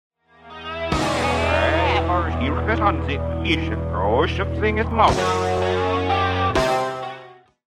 Genere: progressive rock
Rovesciato
Incomprensibile